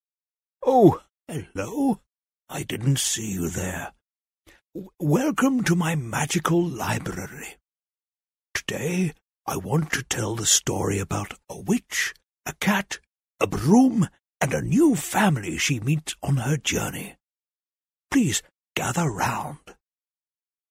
Older Sound (50+)
Warm, articulate British voice with natural authority and clean, confident delivery.
Character / Cartoon
Grandfather Character